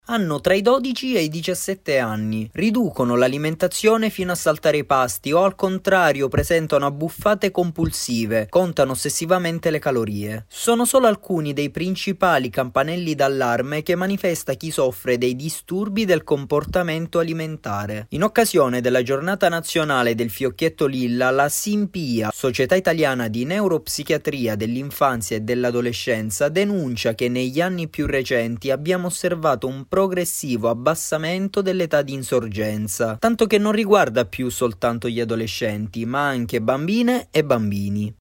Servizio-Grs-16-marzo.mp3